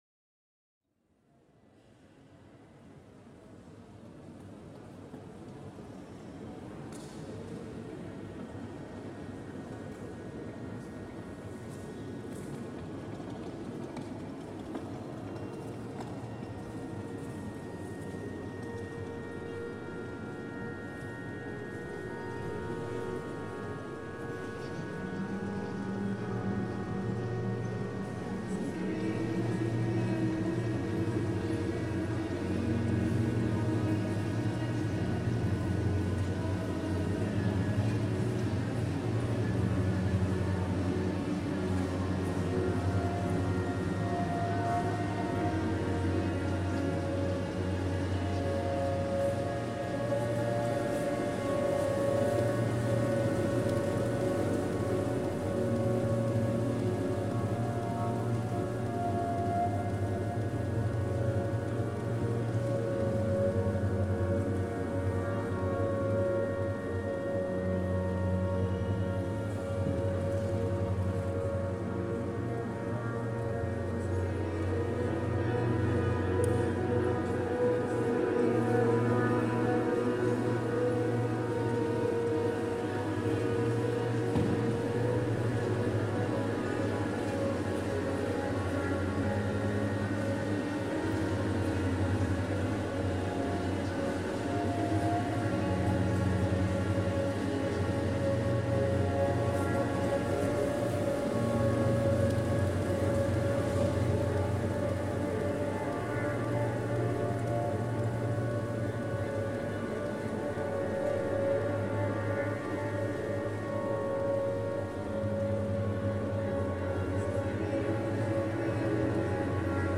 Tirana airport reimagined